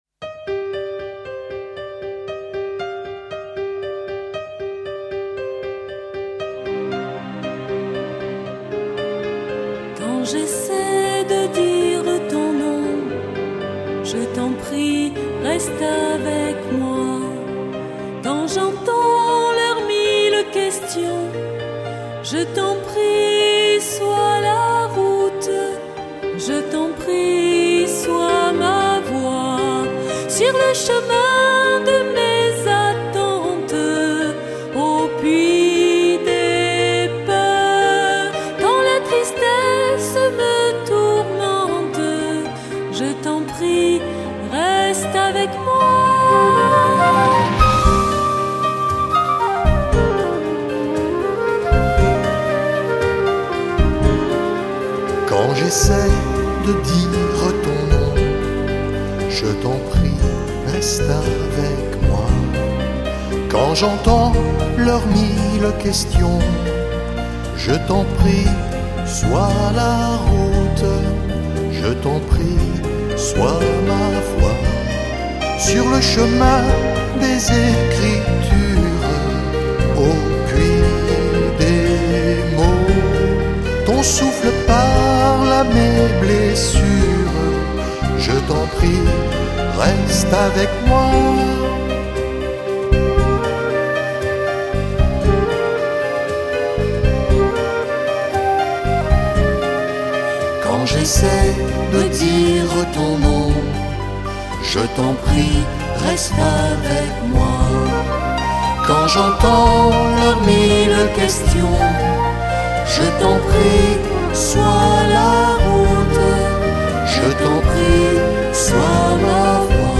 MP3 / Korg / Guitare / Chant / Flute solo / Saxo / ....video